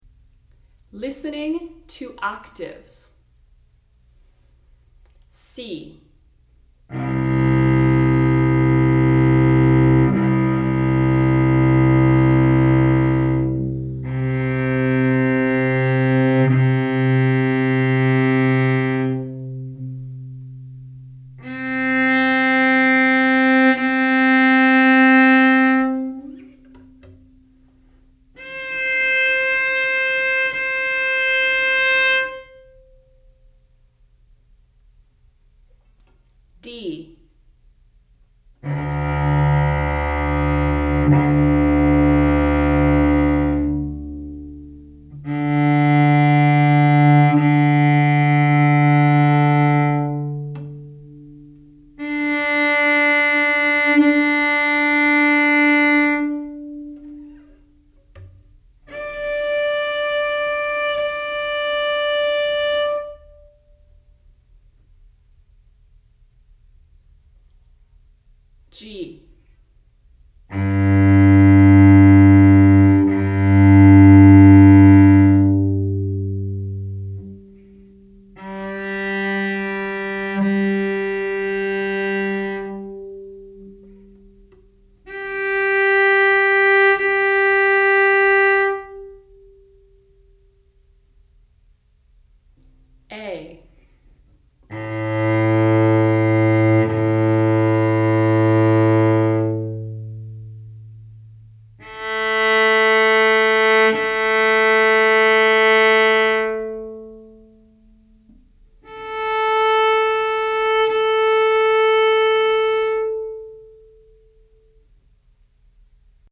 Octaves.mp3